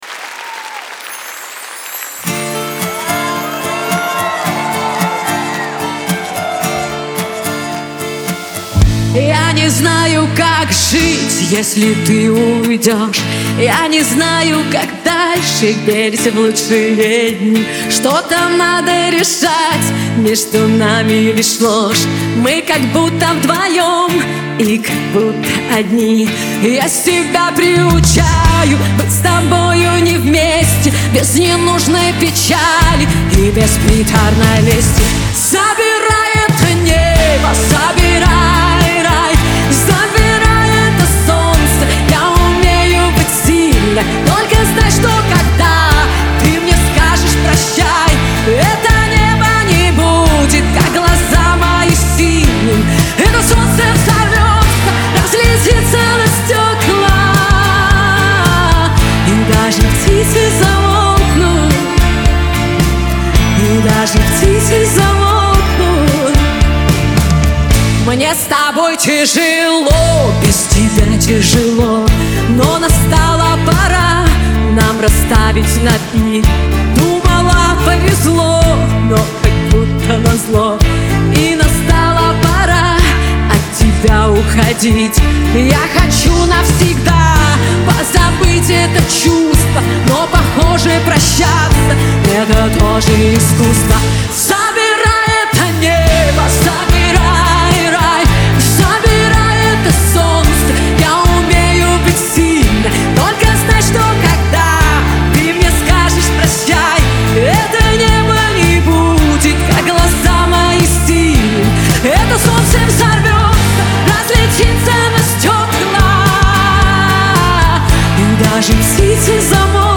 Трек размещён в разделе Русские песни.